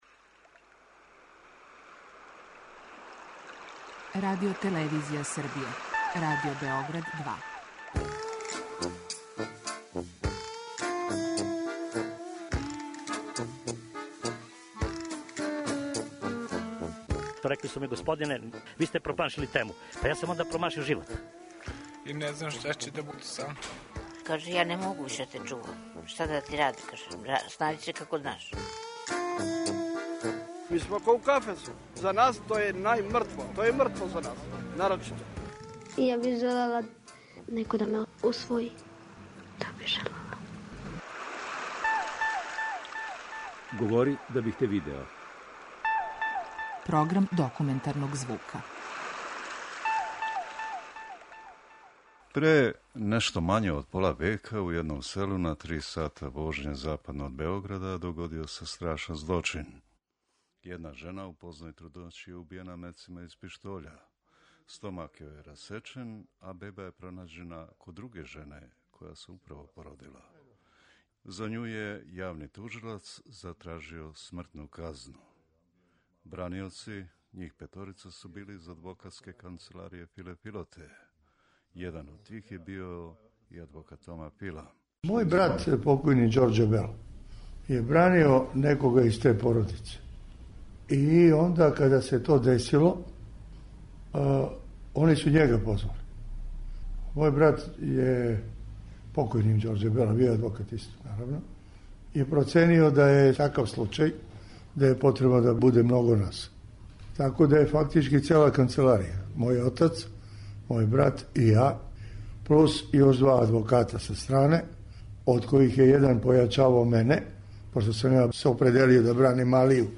Документарни програм
На суђењу је јавни тужилац затражио да буде кажњена смрћу, што би се и догодило да није имала веште браниоце. Један од њих је био и Тома Фила, који ће говорити о овом случају.